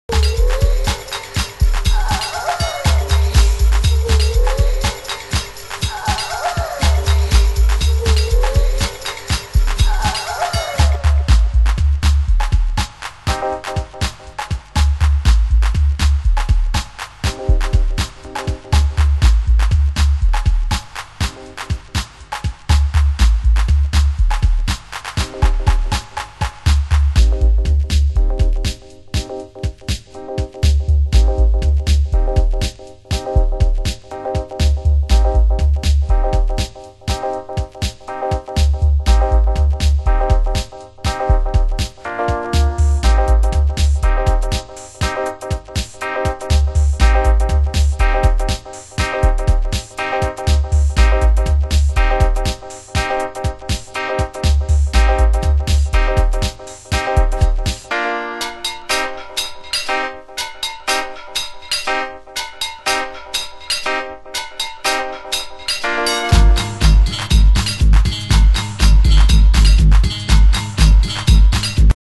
HOUSE MUSIC